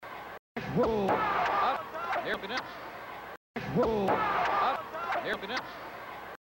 If you have ever watched a tape where the person recording the tape switched it from SP to EP mode or back and forth on an older VCR then you know what sound I am referring to -- the audio sounds slightly modulated -- it's kind of a chirp noise.
I'll include a few second clip of just the chirping noise from various parts of the the tape (it happens 4 times and the clip is replayed once, for a total of 8 instances) and then a slightly longer one where you can hear the noise just at the end.
audio blip.mp3